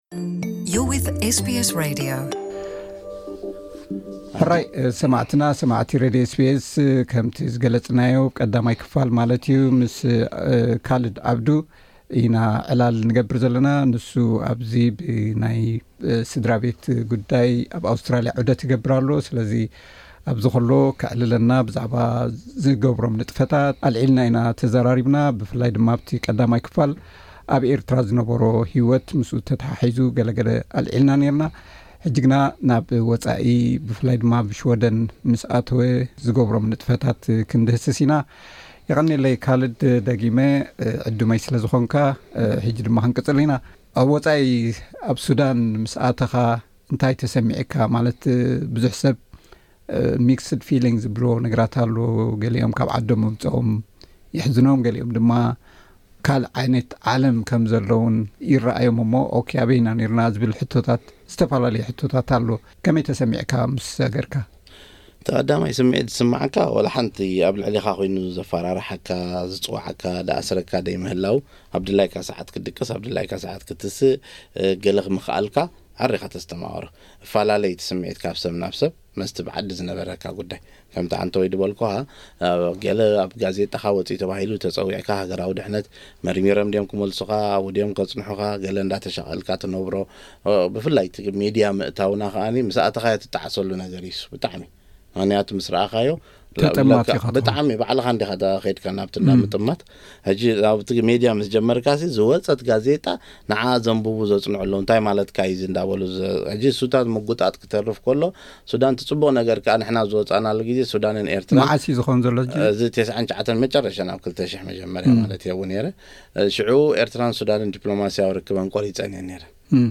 ኣብ ኣውስትራሊያ ኣብ ዝበጽሓሉ ብዛዕባ ታሪኹን ዘካይዶም ንጥፈታትን ሰፊሕ ዕላል ኣካይድናሉ ኣለና። ኣብዚ ናይ ሎሚ ካልኣይ ክፋል ዕላል ኣብ ወጻኢ ሃገራት ዝሰርሖም ስርሓት፡ ምስ መንነቱ ዝተኣሳሰር ዝገጥሞ ብድሆታትን ዝህቦ ምላሽን ገሊጹ ኣሎ።